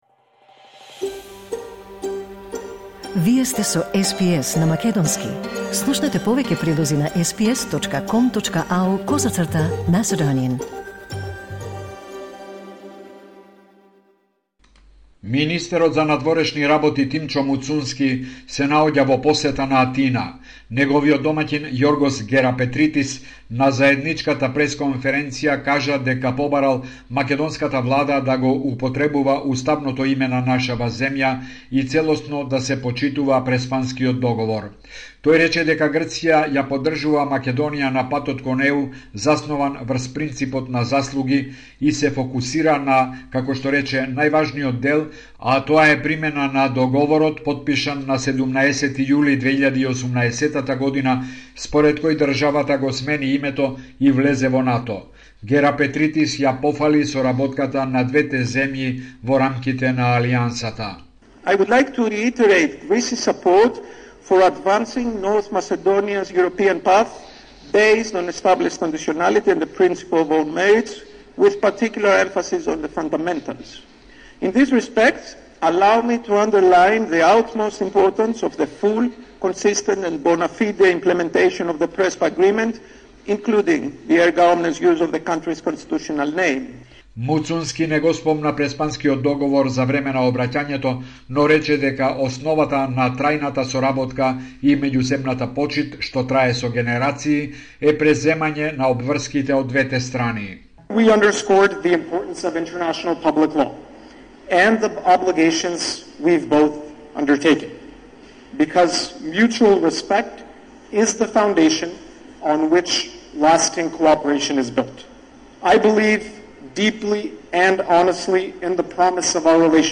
Извештај од Македонија 24 јули 2024